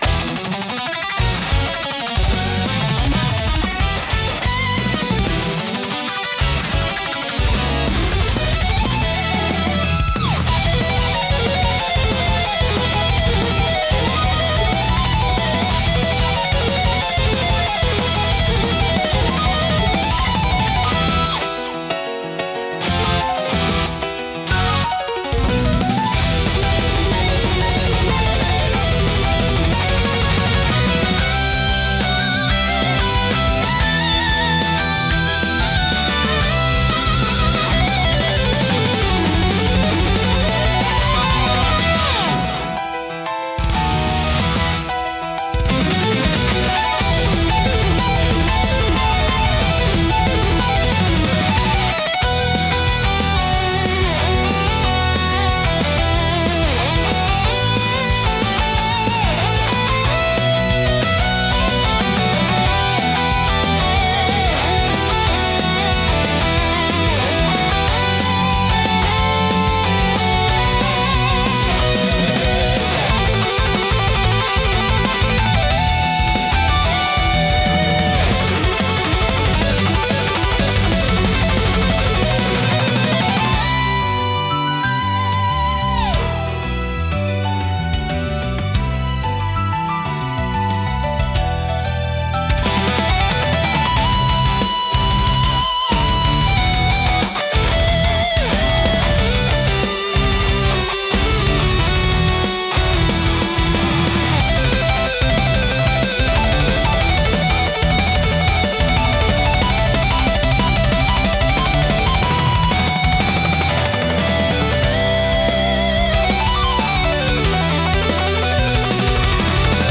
熱狂的で迫力あるサウンドが勝負や決戦の場面を盛り上げ、プレイヤーや視聴者の集中力と興奮を引き出します。